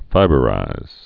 (fībə-rīz)